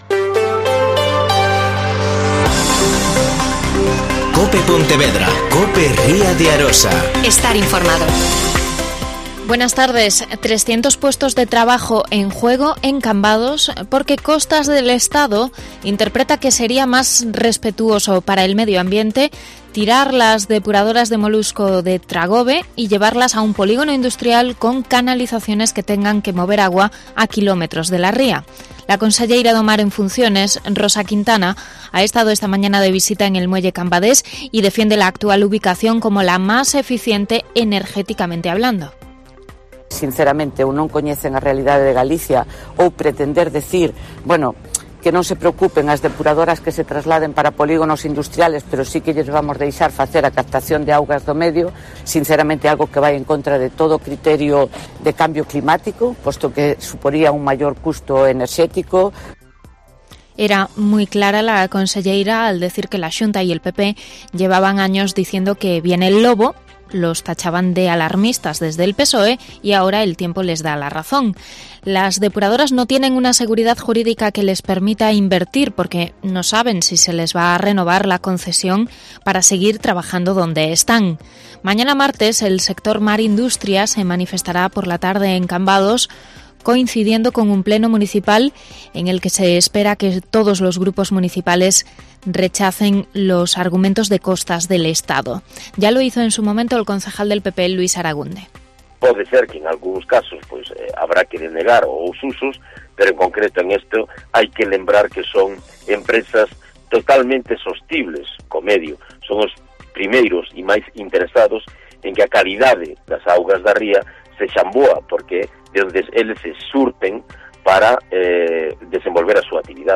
Mediodía COPE Pontevedra y COPE Ría de Arosa (Informativo 14:20h)